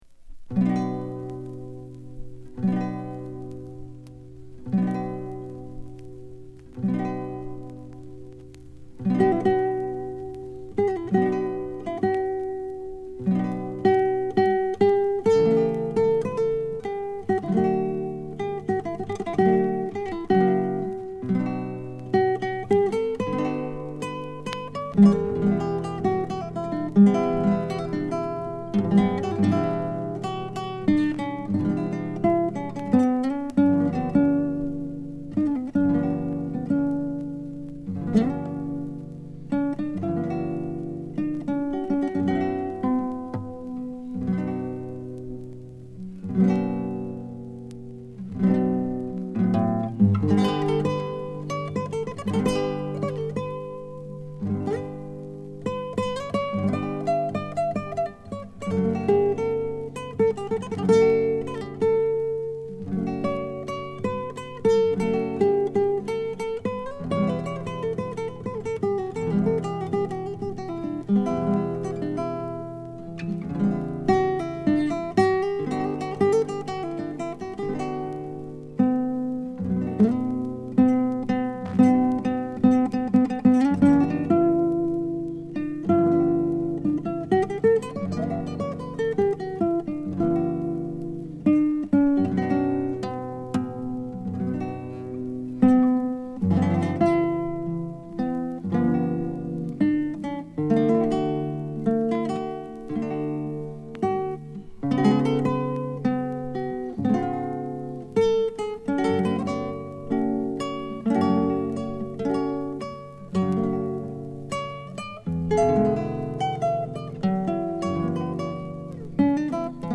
優雅でリラックスを誘うギタープレイが沁みる1枚に！